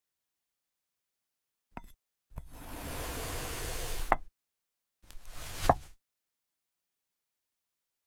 Glass Apple ASMR – clean sound effects free download
clean Mp3 Sound Effect Glass Apple ASMR – clean cuts, calm sounds.